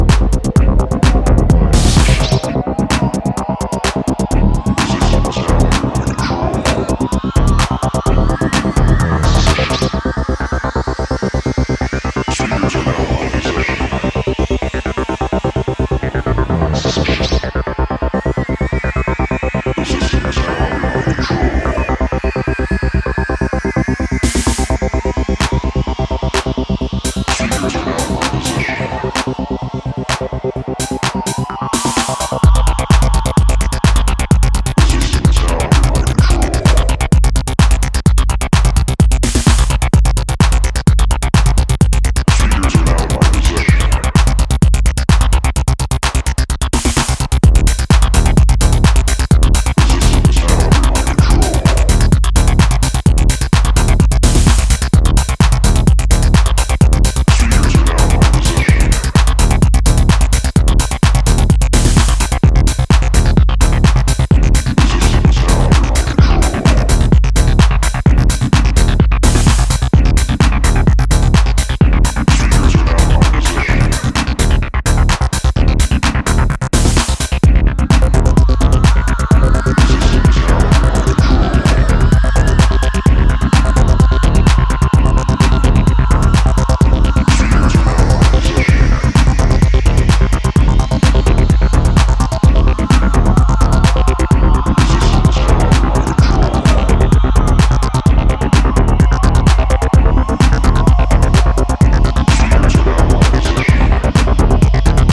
Pure, hypnotic and sharp acid techno